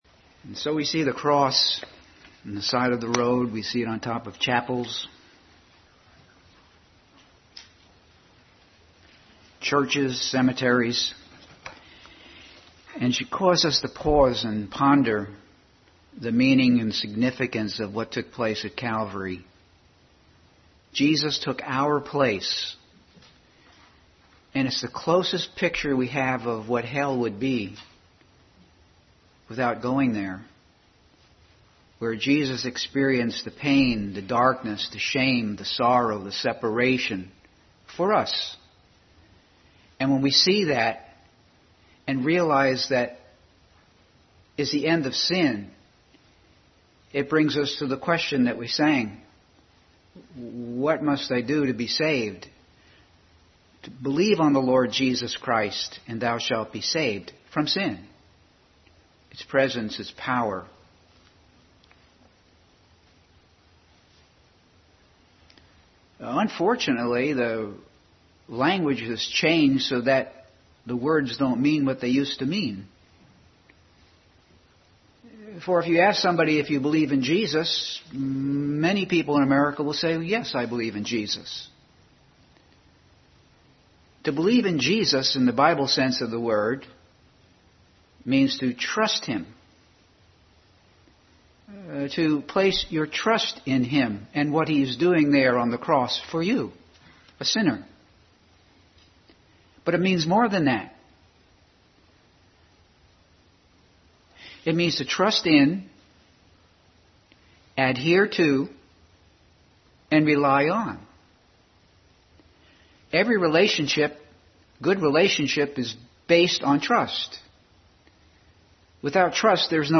Bible Text: Luke 9:23, 23:34 & 43, John 19:26-27, Matthew 27:4-6, John 18:22-23, Luke 23:46 | Family Bible Hour message.